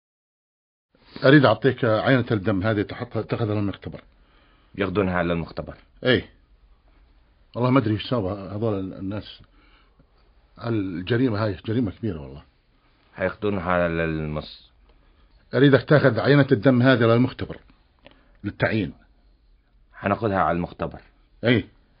Два или три мужчины разговаривают на арабском